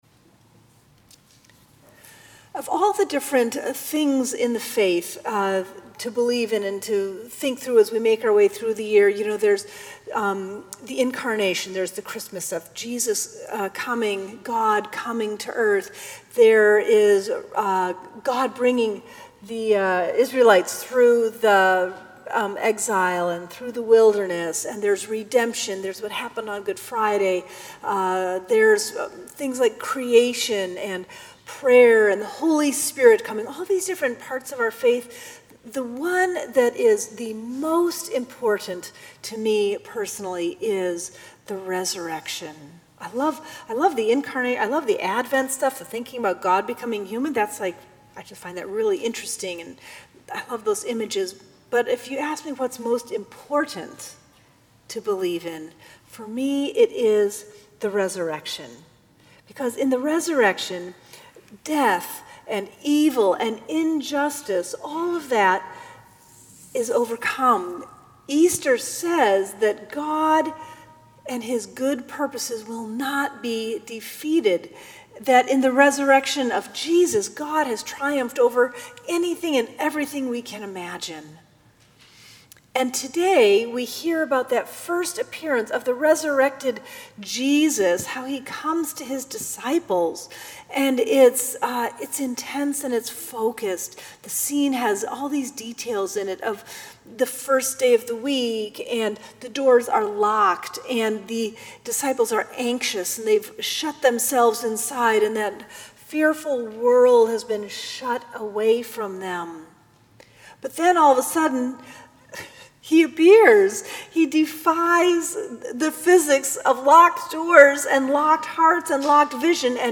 Sermons
April 23, 2017 Second Sunday of Easter